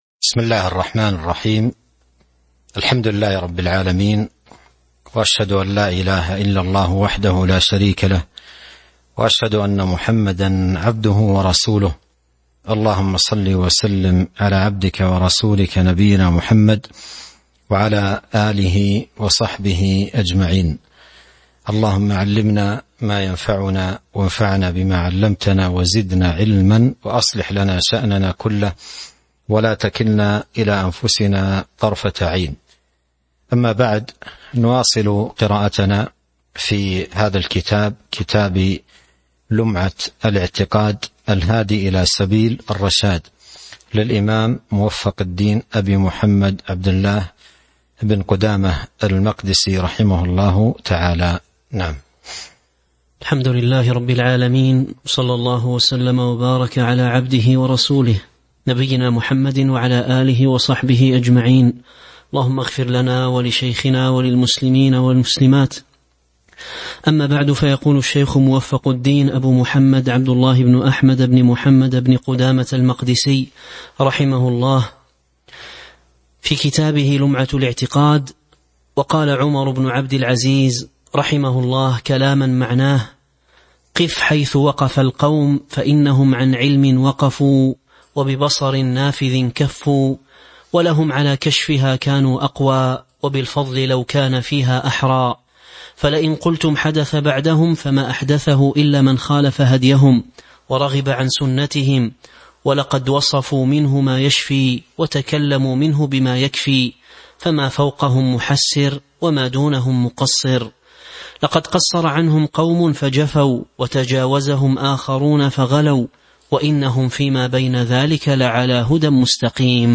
تاريخ النشر ١٨ ذو الحجة ١٤٤٢ هـ المكان: المسجد النبوي الشيخ